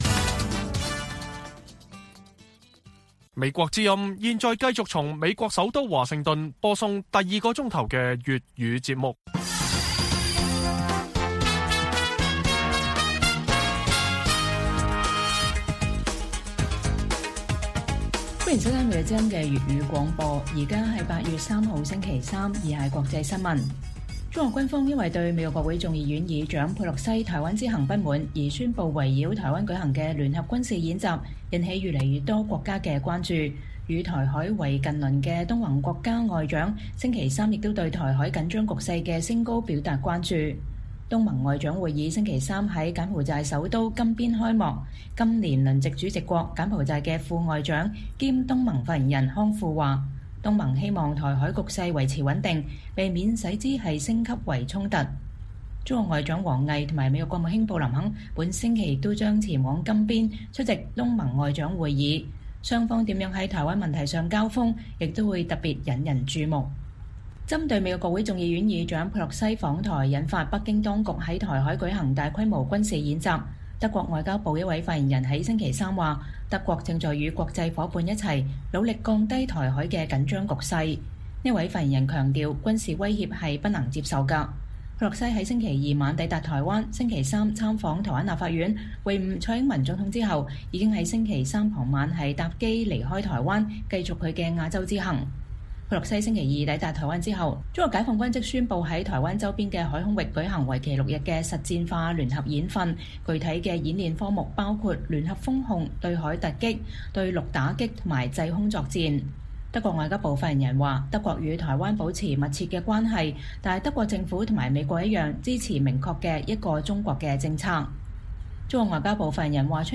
粵語新聞 晚上10-11點: 港府及立法會附和中共口徑發聲明譴責佩洛西訪台